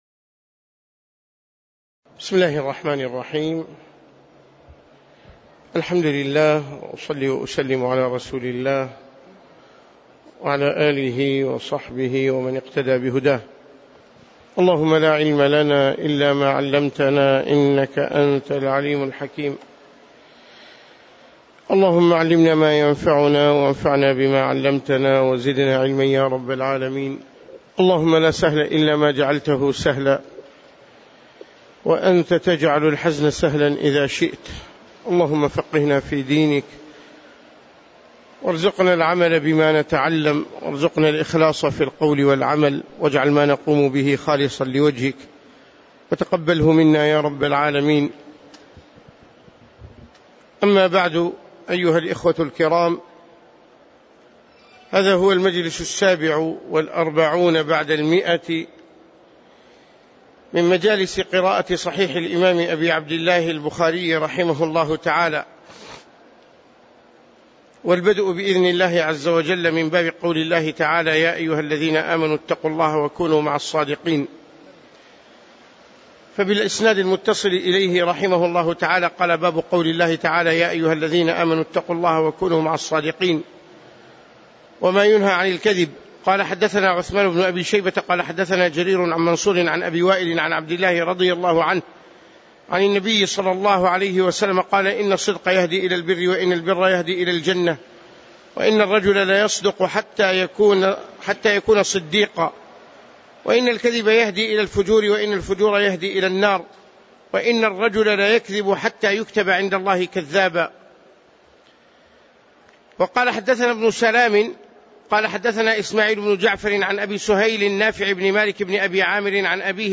تاريخ النشر ١٥ محرم ١٤٣٩ هـ المكان: المسجد النبوي الشيخ